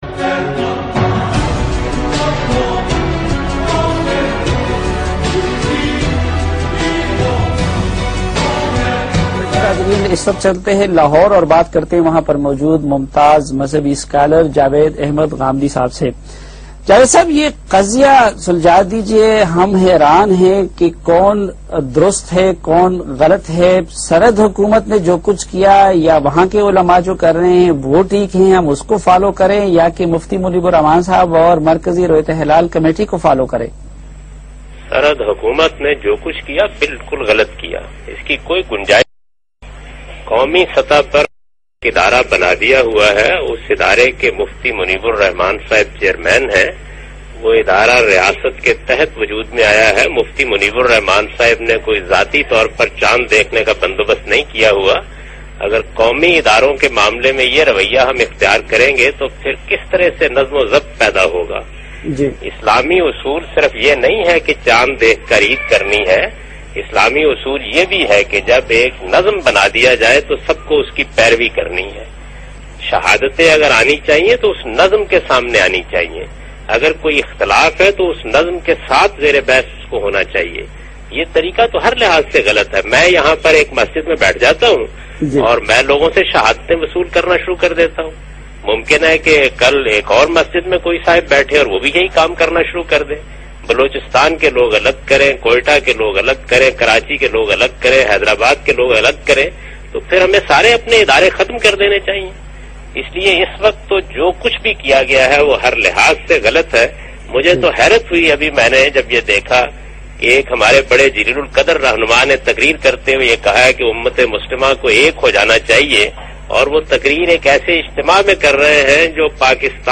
Category: TV Programs / Geo Tv / Questions_Answers /
Javed Ahmad Ghamidi's Comments on Royyat-e-Hilal Controversy in Geo Tv's program Jirga with Saleem Safi.
جاوید احمد غامدی جیو ٹی وی کے پروگرام جر گہ میں رویت حلال کے مسئلہ پر گفتگو کر رہے ہیں